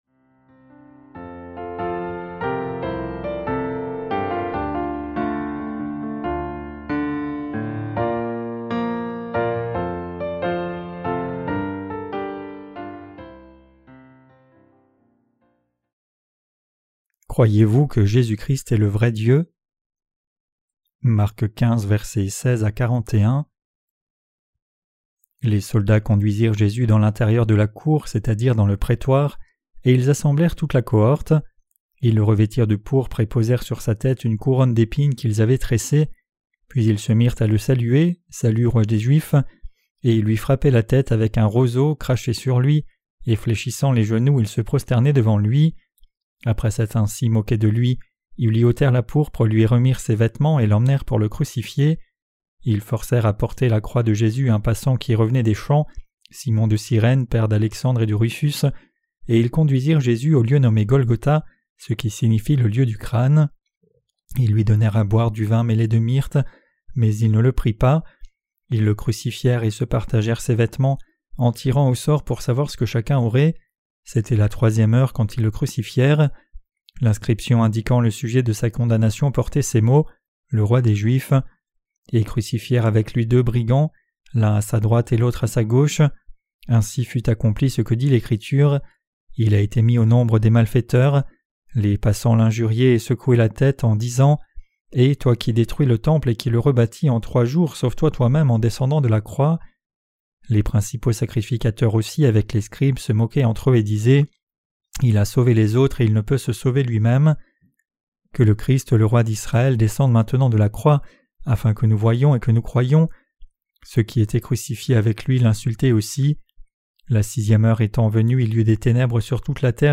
Sermons sur l’Evangile de Marc (Ⅲ) - LA BÉNÉDICTION DE LA FOI REÇUE AVEC LE CŒUR 11.